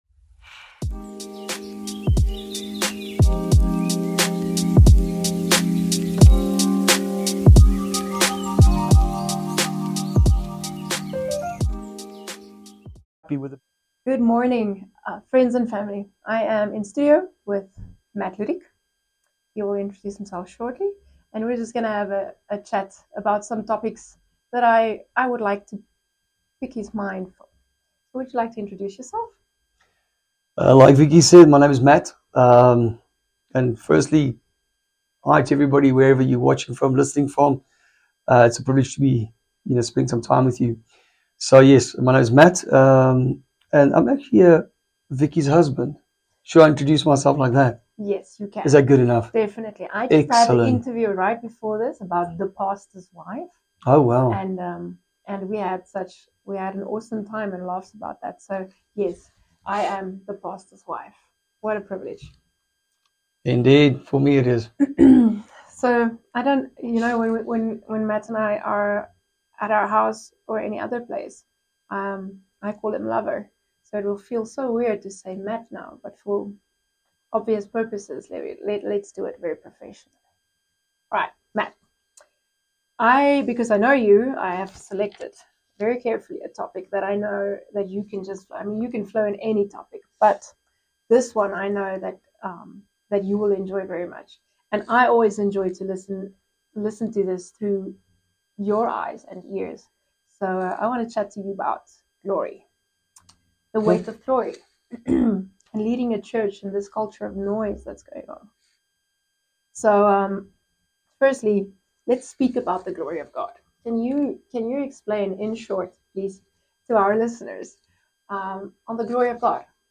Tune in for real talk, faith-filled wisdom, and a reminder that His glory is worth everything.